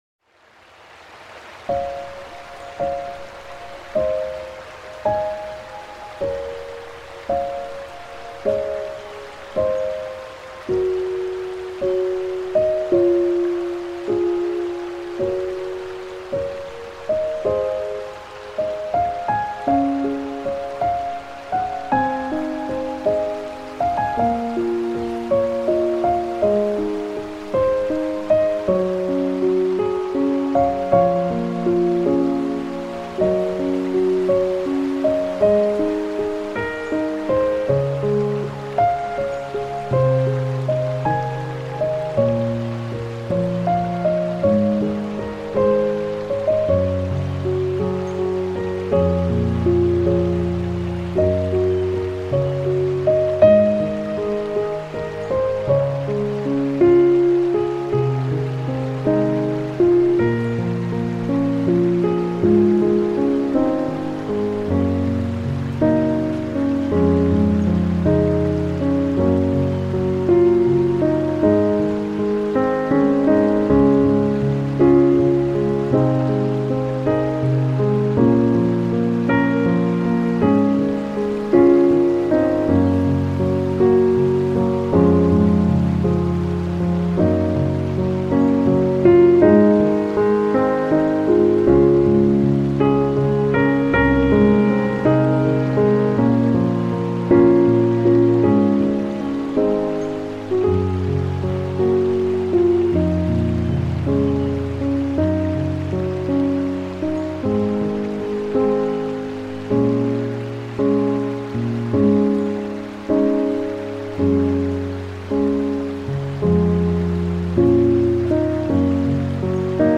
音乐风格；New Age